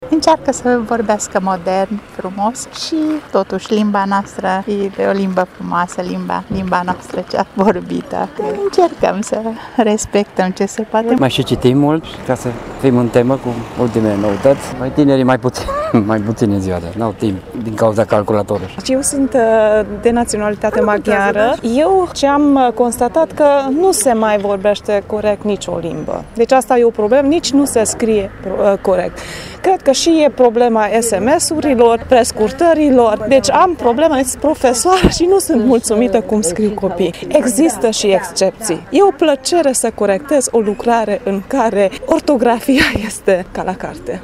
Tîrgumureșenii spun că limba română se degadează constant, iar de vină sunt și mijloacele de comunicare moderne.